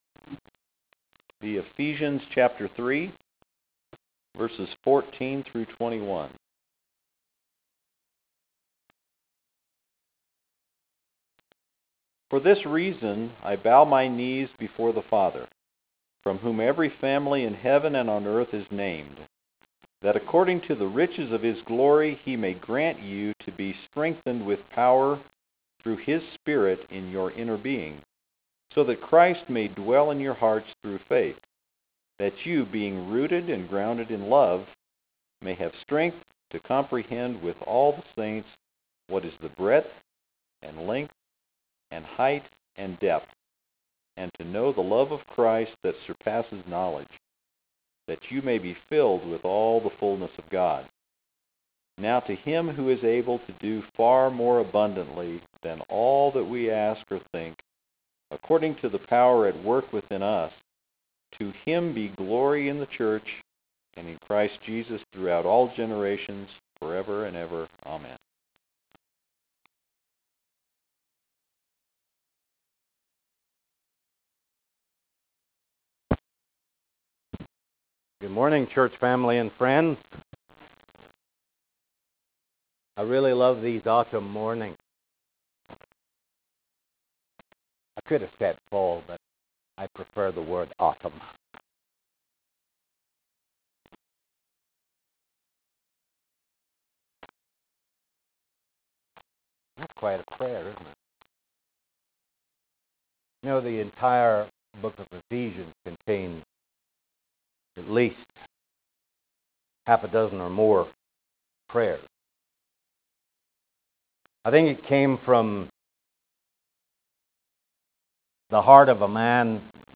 Oct 5 2014 AM sermon